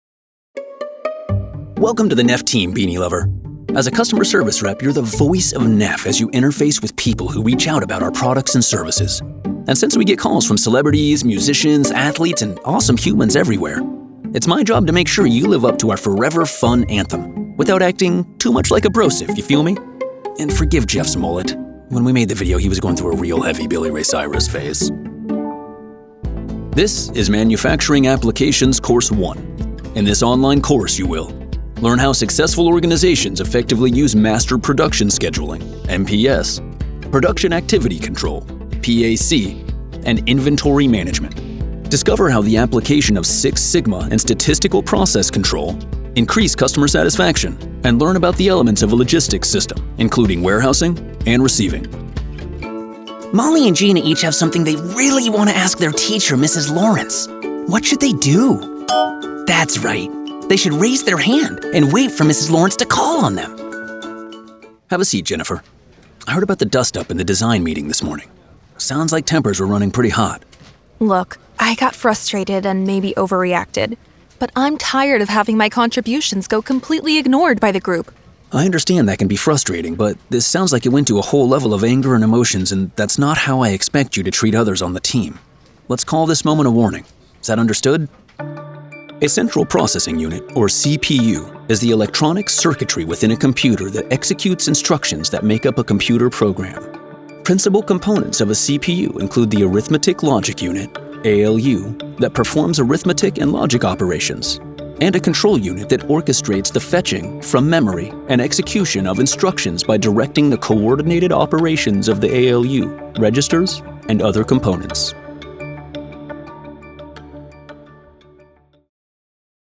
Demos
Neumann TLM 103 Microphone
Professionally Treated Recording Space